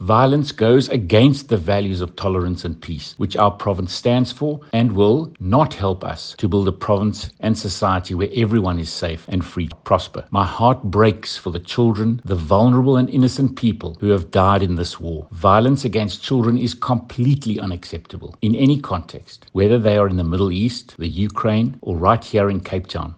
Winde says he appeals for restraint and constructive dialogue over the Middle East violence: